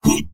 文件 文件历史 文件用途 全域文件用途 Enjo_atk_04_2.ogg （Ogg Vorbis声音文件，长度0.3秒，189 kbps，文件大小：8 KB） 源地址:地下城与勇士游戏语音 文件历史 点击某个日期/时间查看对应时刻的文件。